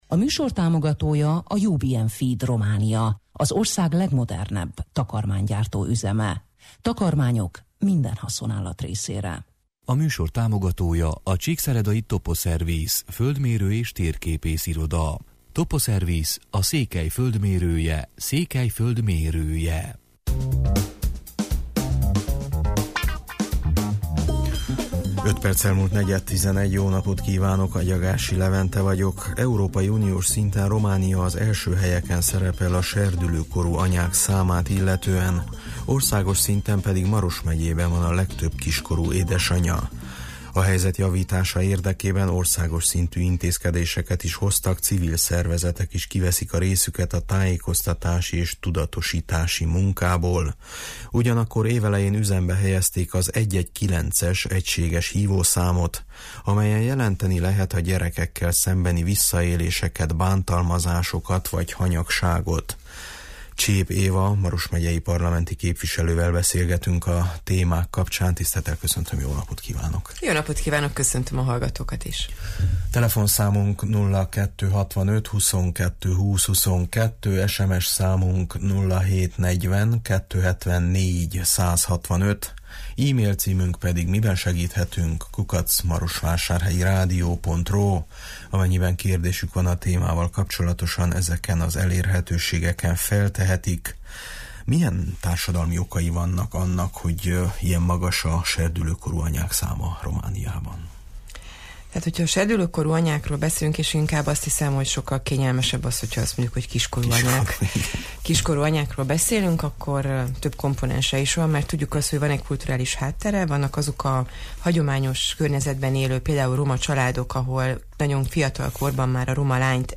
Csép Éva Andrea Maros megyei parlamenti képviselővel beszélgetünk a téma kapcsán.